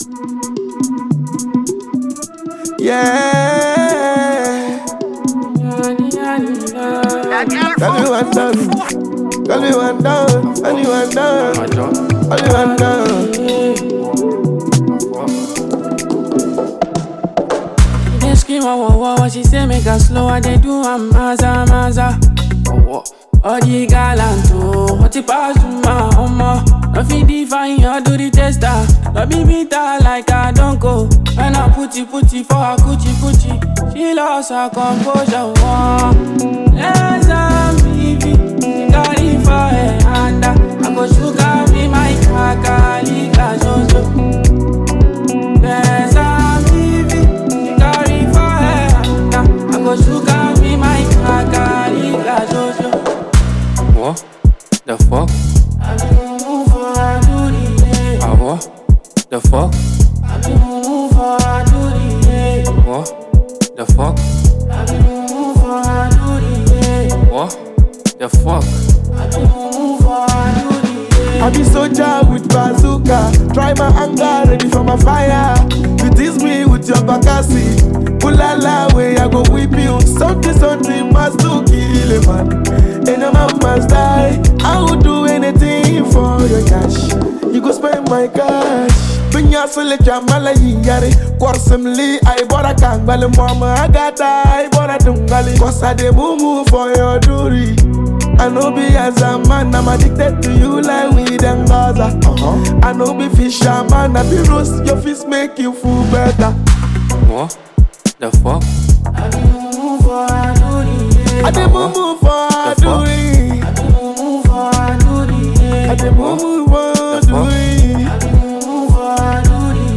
groovy and street-inspired tune
smooth delivery
Ghana Afrobeat MP3